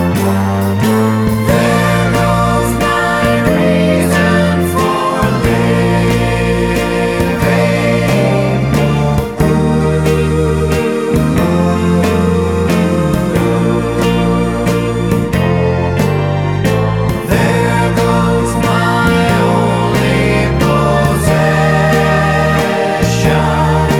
No Backing Vocals Crooners 2:50 Buy £1.50